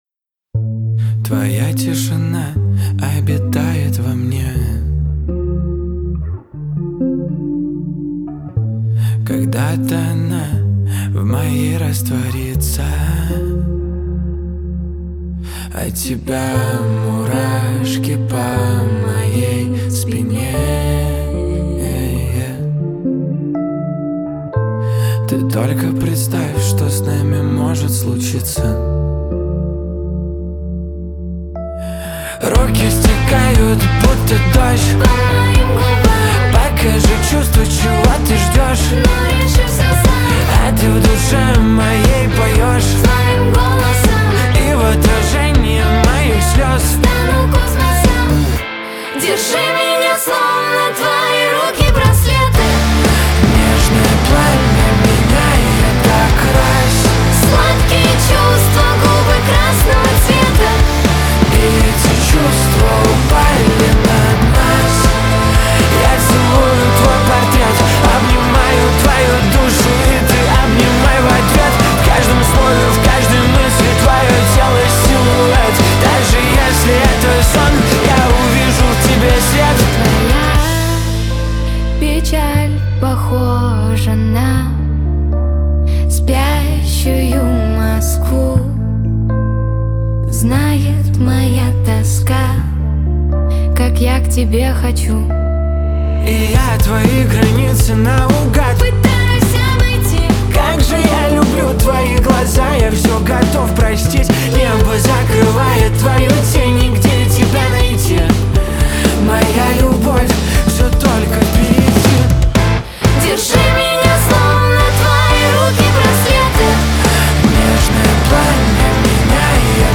Качество: 320 kbps, stereo
Поп музыка, Русские поп песни, Саундтреки
Актриса и певец записали совместную композицию для фильма